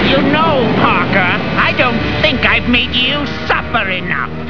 From Spider-Man: The Animated Series.